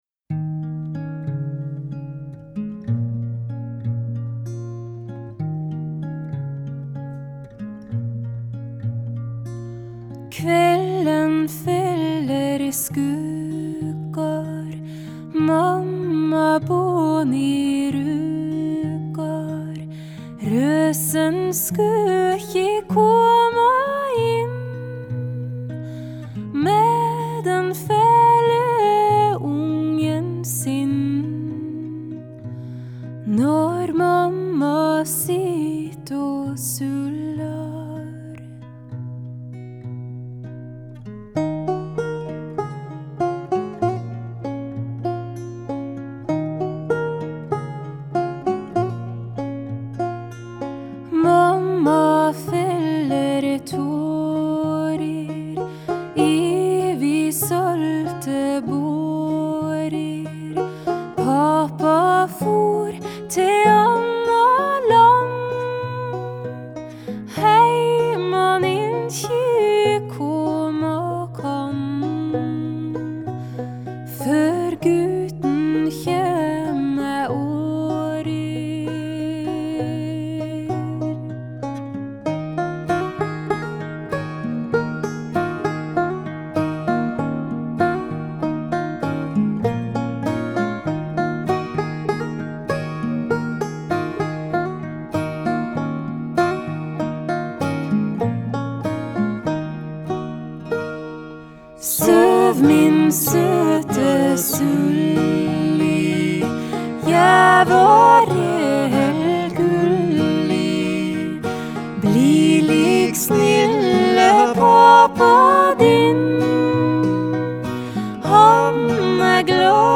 Genre: Folk-Rock, Folk-Pop, Folk
vocals, Guitar, banjo, harmonica, percussion, accordion